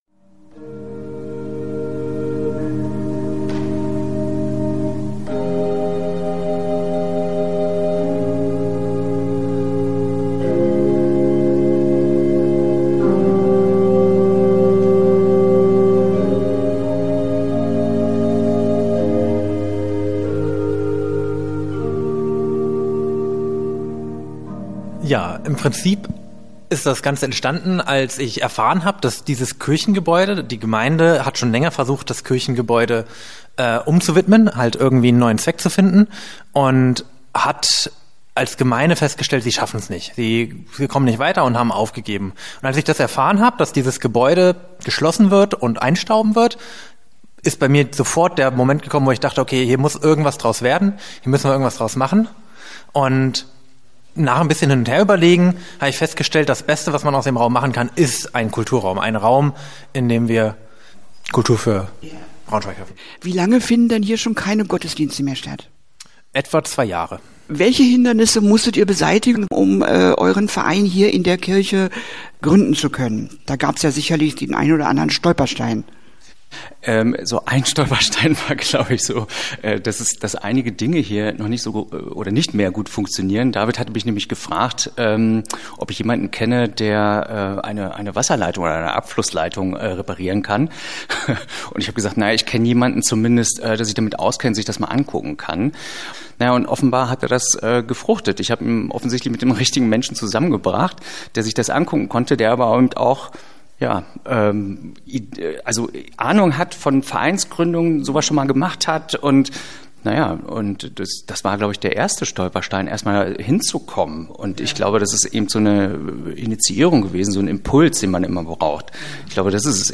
Interview-Kulturraum-Jakobi_sys.mp3